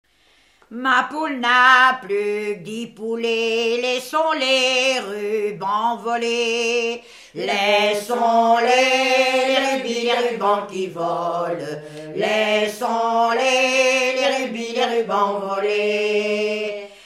Mémoires et Patrimoines vivants - RaddO est une base de données d'archives iconographiques et sonores.
Genre énumérative
chansons traditionnelles islaises